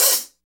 HAT REAL H0E.wav